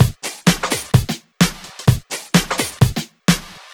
VMH1 Minimal Beats 10.wav